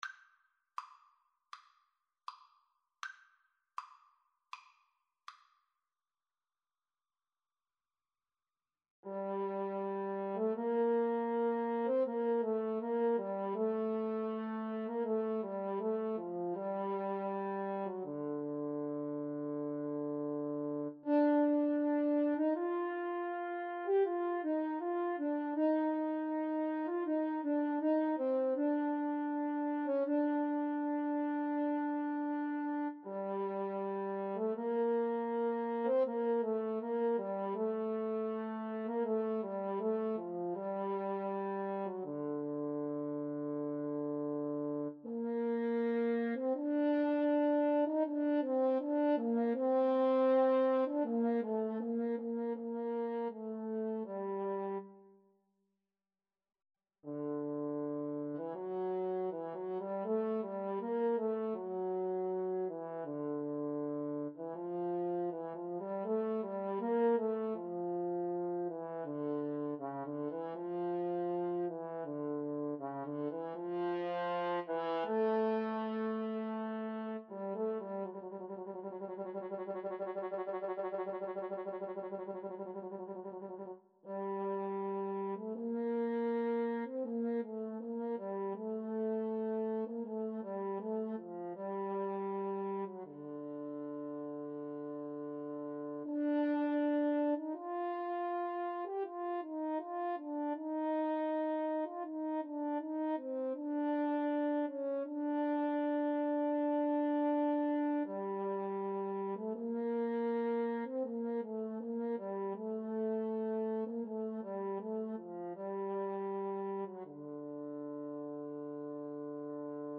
Play (or use space bar on your keyboard) Pause Music Playalong - Player 1 Accompaniment reset tempo print settings full screen
G minor (Sounding Pitch) (View more G minor Music for Clarinet-French Horn Duet )
Andante